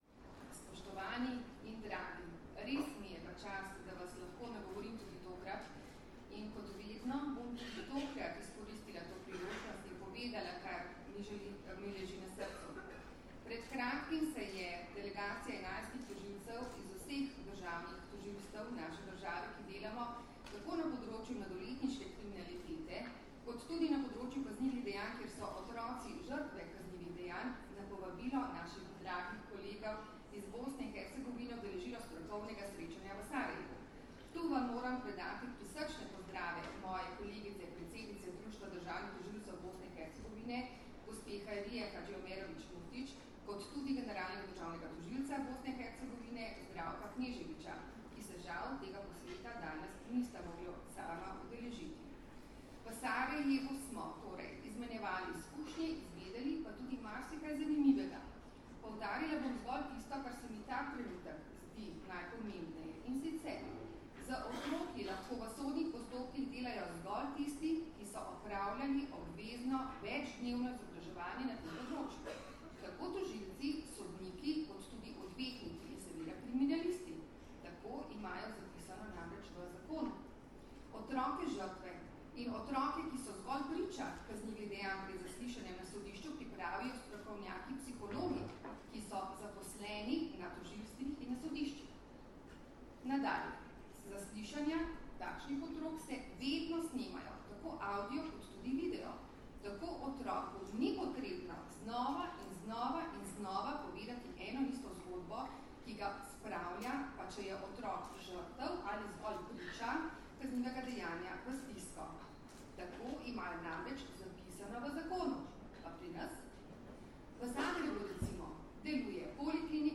V Kongresnem centru Brdo, Predoslje, se je danes, 6. aprila 2017, pod naslovom V imenu otroka začel dvodnevni posvet na temo problematike poznavanja otroka za kvalitetnejšo obravnavo v postopkih, ki ga organizirata Policija in Društvo državnih tožilcev Slovenije v sodelovanju s Centrom za izobraževanje v pravosodju.
Zvočni posnetek pozdravnega nagovora